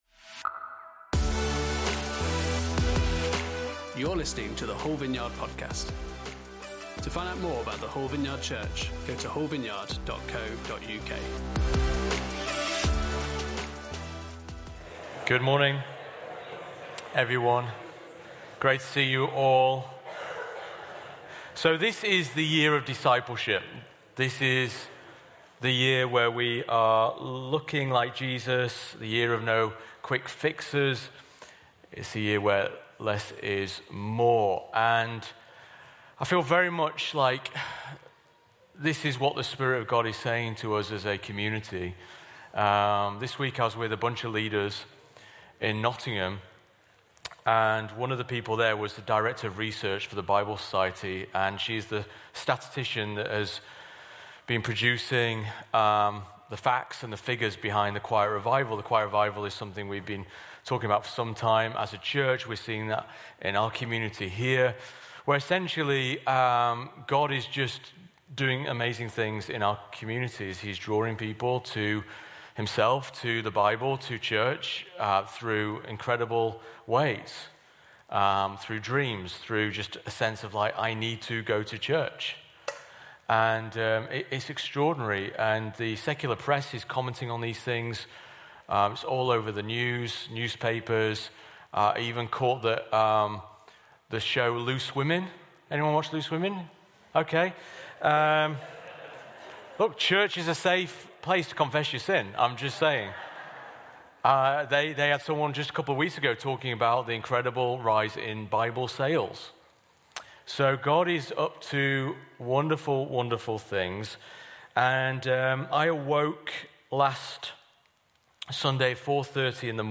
Series: Discipleship: The Beatitudes Service Type: Sunday Service